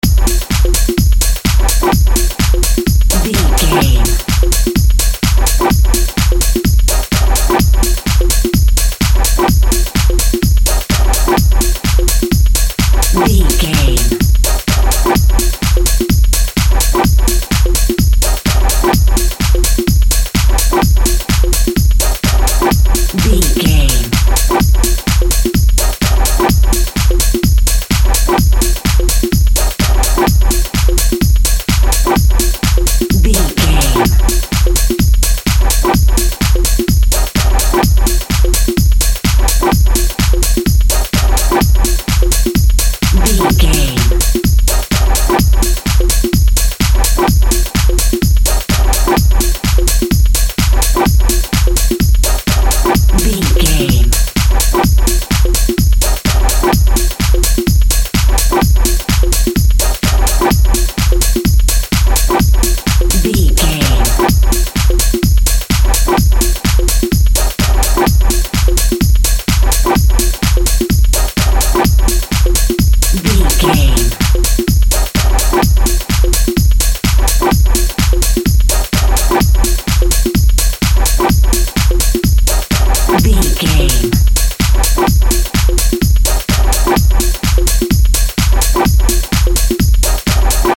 Atonal
energetic
hypnotic
drum machine
house
techno
electro house
club music
synth lead
synth bass
synth drums